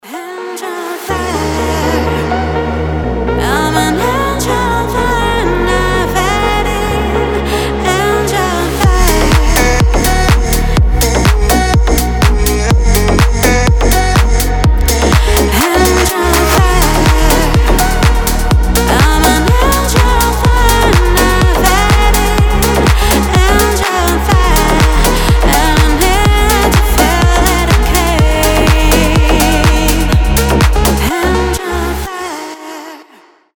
• Качество: 320, Stereo
Electronic
басы
progressive house
красивый женский голос
slap house
Модно звучащая современная танцевальная музыка для звонка